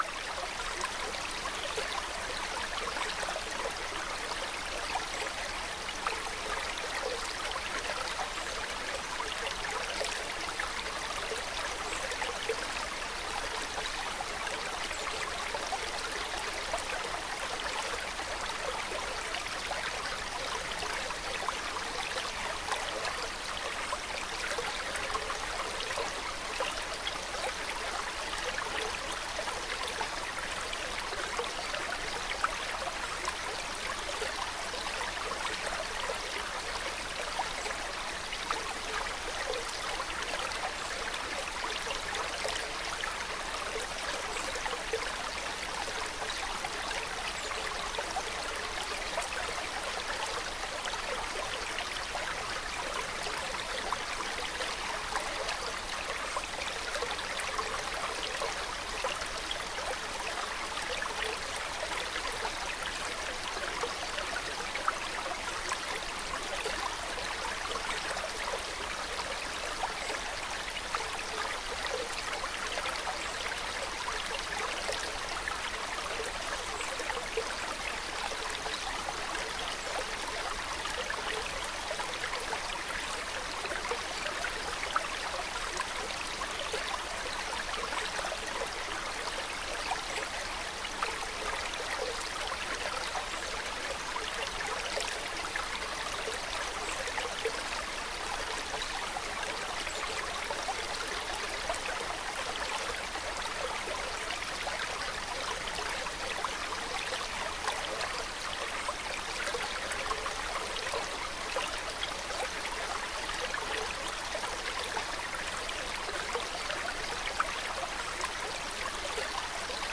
Nature / Loops / Stream